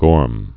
(gôrm)